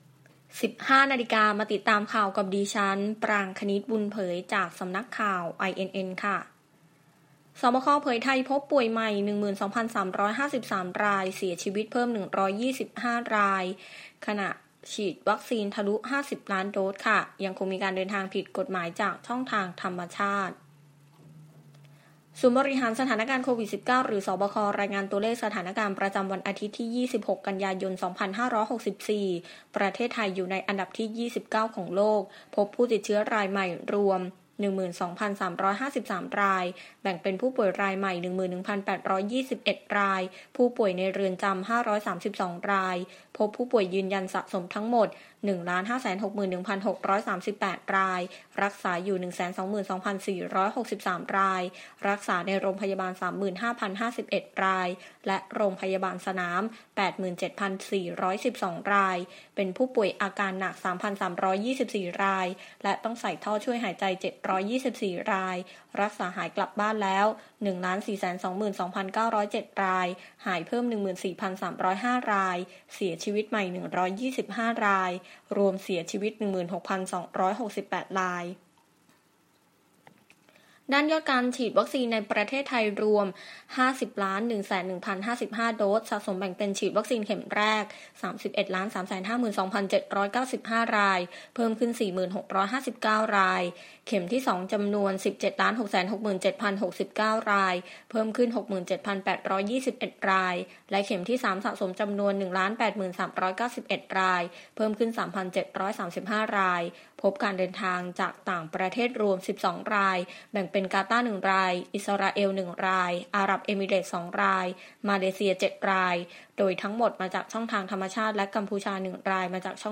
ข่าวต้นชั่วโมง 15.00 น.
อย่างไรก็ตาม ระหว่างการกล่าวมอบนโยบายนายกรัฐมนตรี ได้มีการไอ ออกไมค์อยู่เป็นระยะหลายครั้ง จึงได้มีการขอโทษทุกคน ก่อนถามประชาชนว่ารู้ไหมว่านายกฯ พูดอะไร